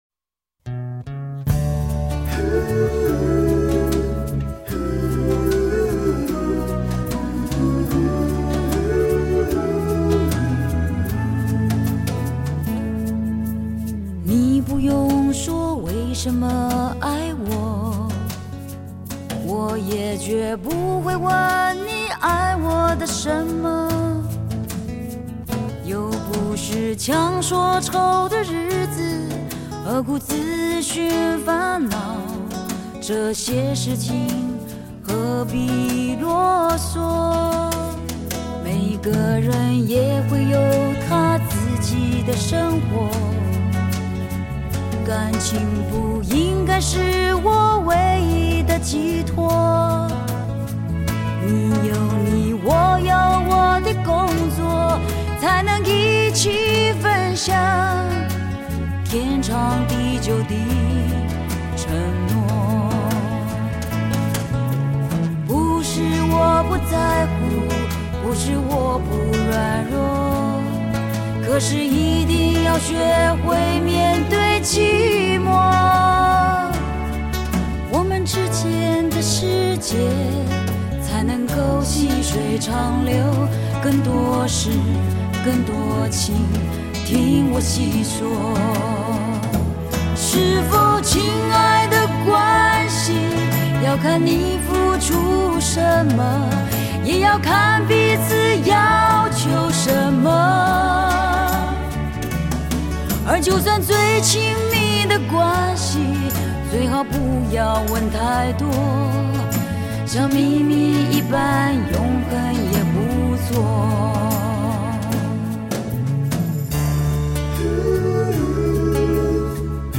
可以唱得極高極具力量，
也可以溫柔可愛細細訴說，